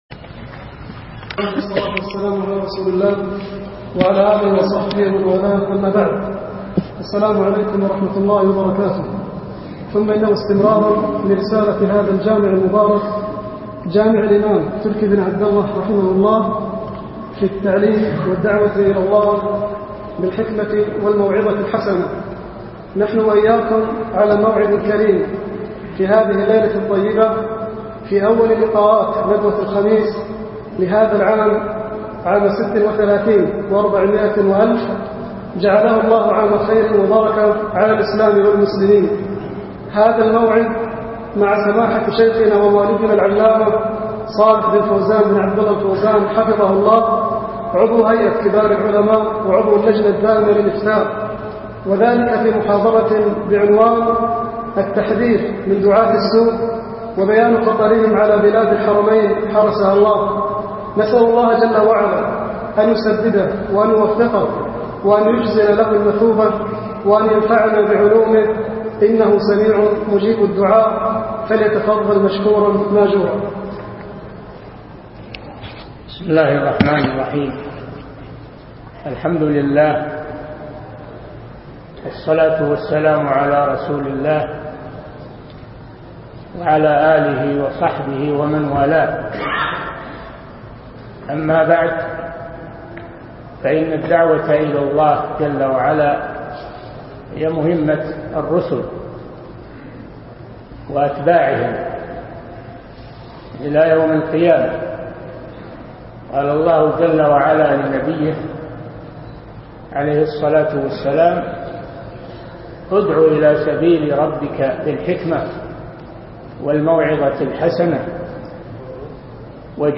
محاضرة
في الجامع الكبير - جامع الإمام تركي بن عبد الله - بمدينة الرياض.